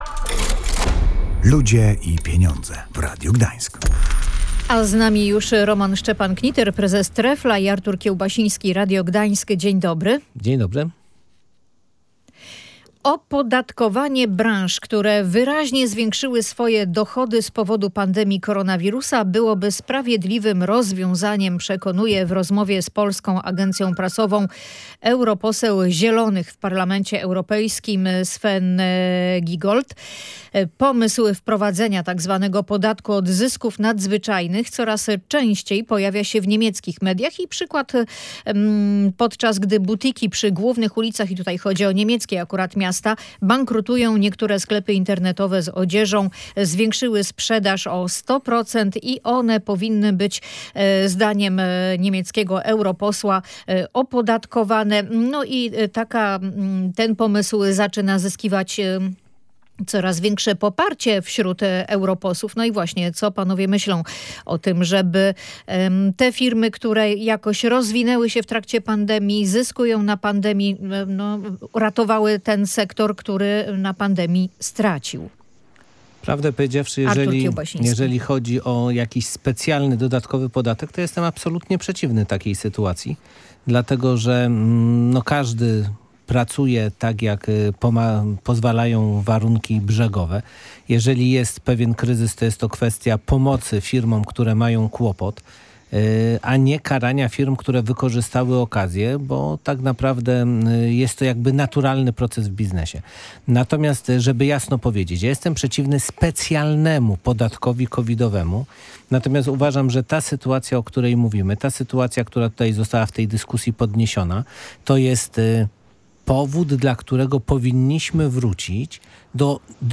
O to pytaliśmy w piątkowym wydaniu audycji „Ludzie i Pieniądze”.